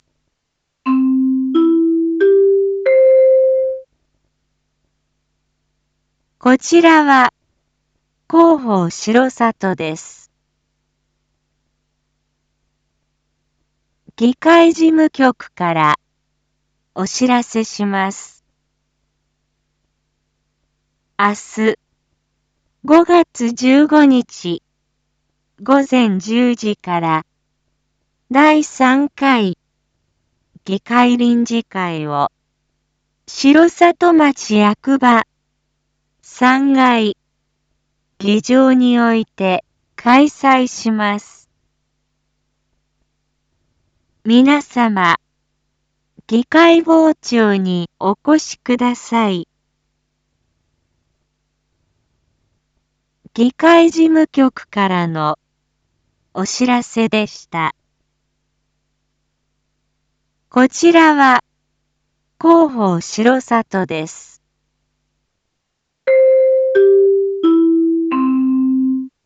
Back Home 一般放送情報 音声放送 再生 一般放送情報 登録日時：2025-05-14 19:01:10 タイトル：①第３回議会臨時会 インフォメーション：こちらは広報しろさとです。